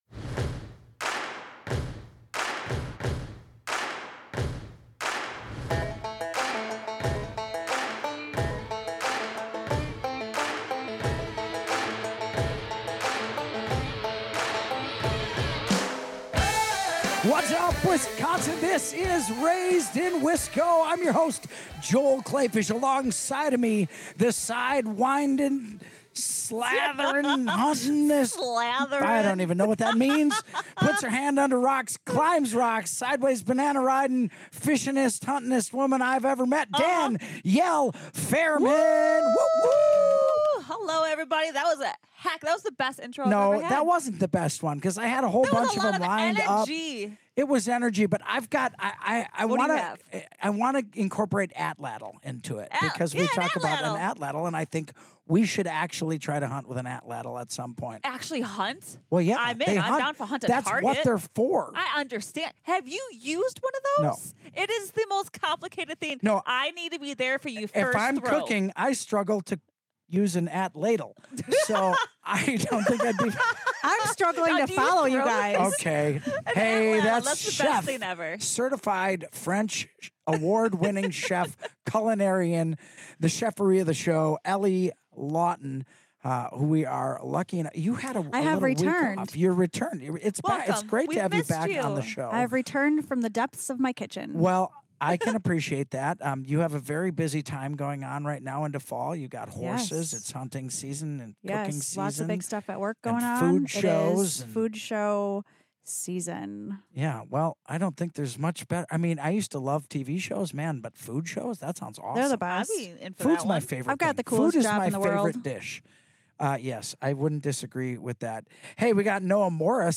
Tune in for a heartwarming and earthy conversation full of Midwest charm, all natural homemade goodness, and a reminder that sometimes the best things come from what’s grown—and made—close to home.